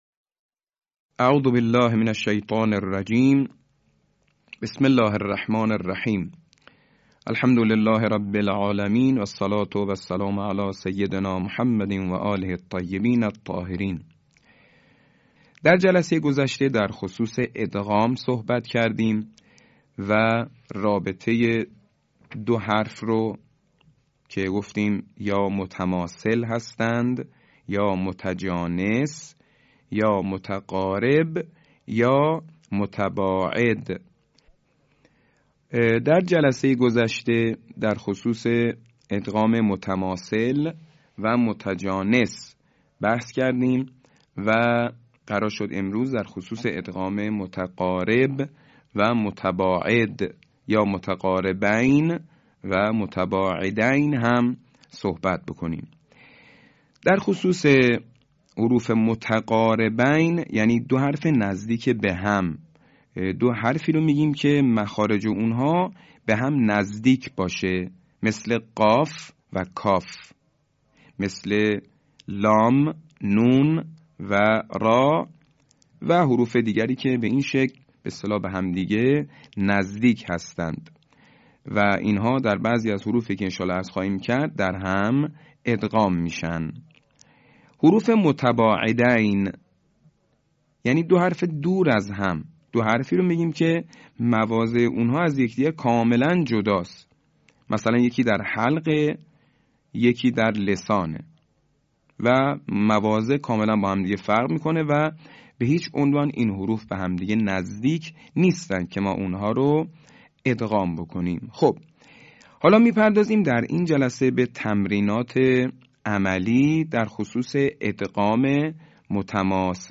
صوت | آموزش ادغام متقاربین و متباعدین